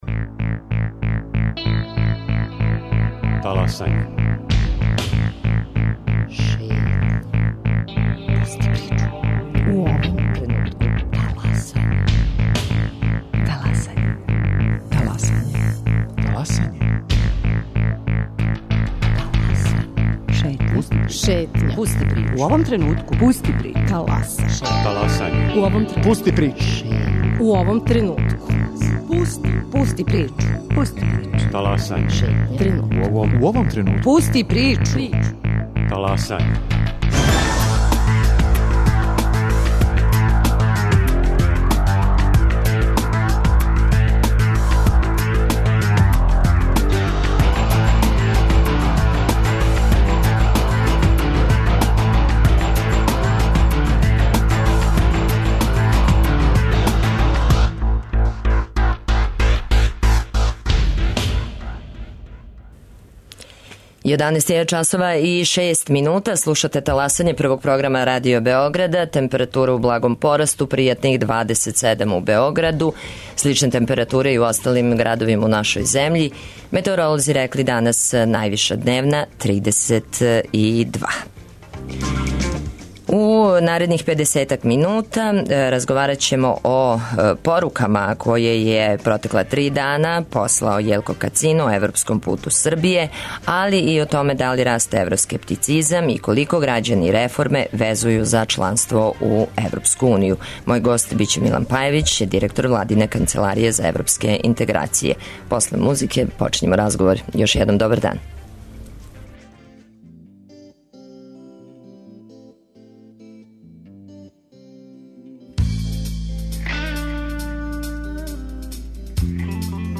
Гост Таласања Милан Пајевић, директор владине Канцеларије за европске интеграције.